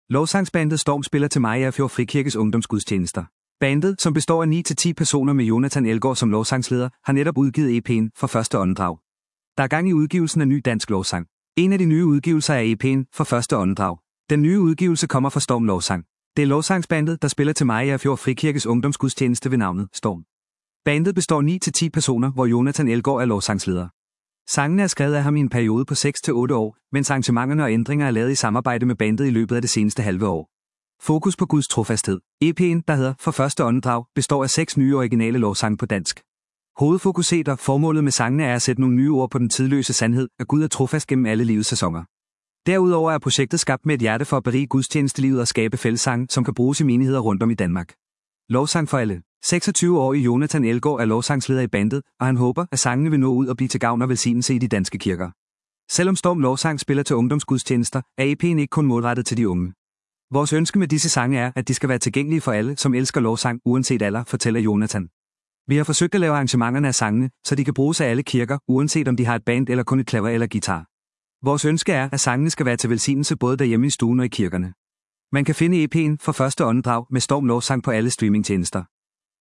består af 6 nye originale lovsange på dansk.